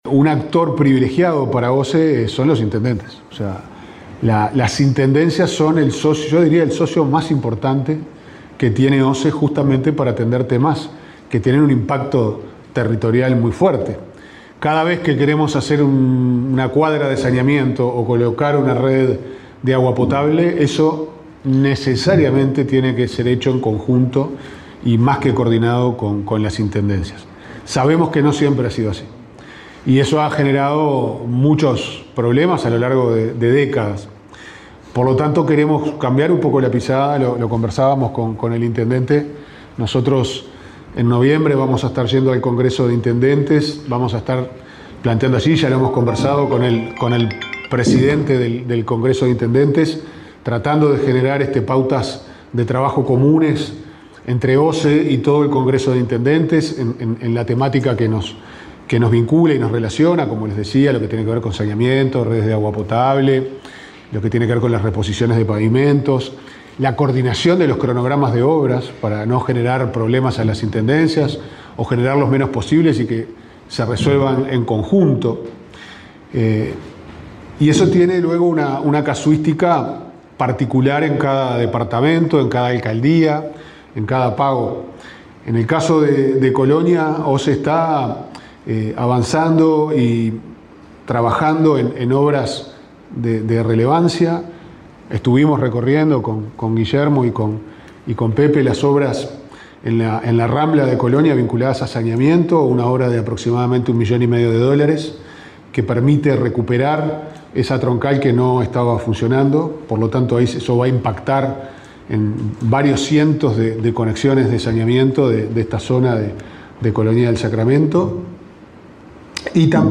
Declaraciones del presidente de OSE, Pablo Ferreri
Declaraciones del presidente de OSE, Pablo Ferreri 09/10/2025 Compartir Facebook X Copiar enlace WhatsApp LinkedIn El presidente de OSE, Pablo Ferreri, informó en Colonia sobre la construcción de una planta de tratamiento de aguas residuales en Juan Lacaze y el avance de las obras de saneamiento en la costanera de Colonia del Sacramento.